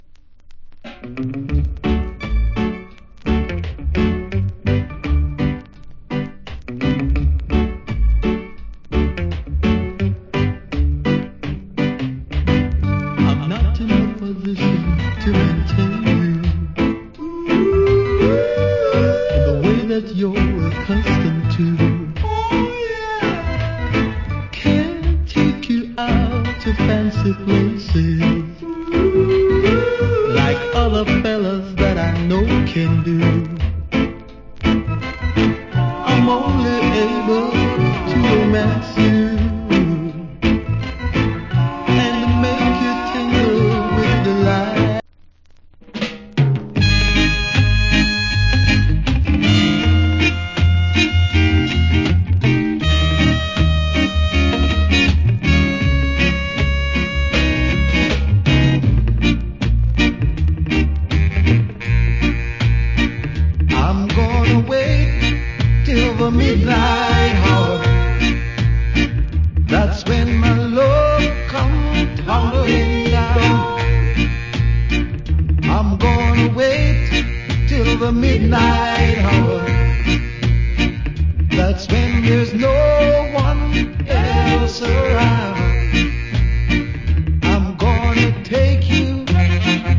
Old Hits Rock Steady Vocal.